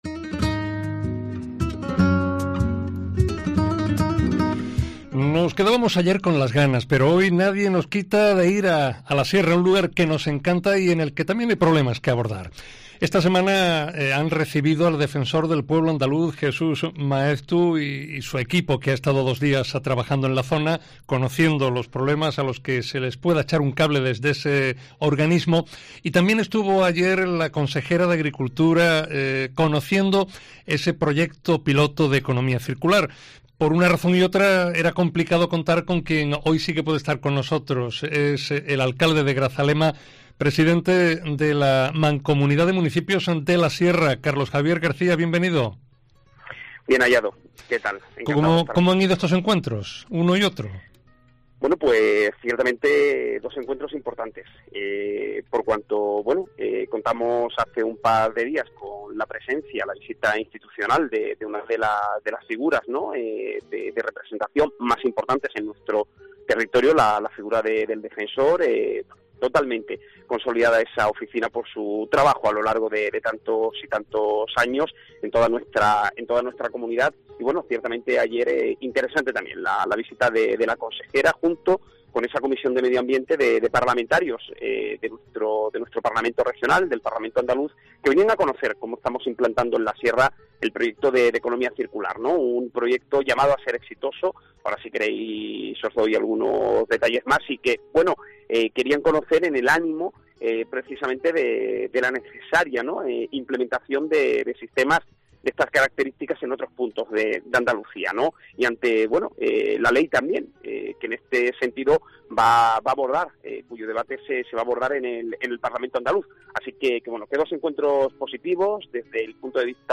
Carlos García, presidente de la Mancomunidad, repasa los déficits sanitarios, transporte público, financiación o medioambientales presentados esta semana al Defensor del Pueblo